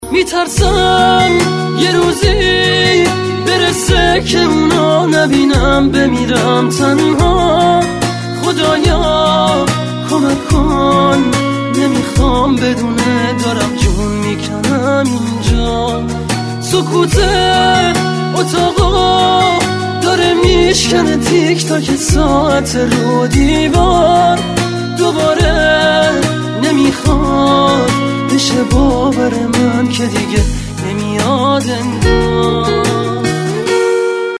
رینگتون زیبا، احساسی و رمانتیک(با کلام)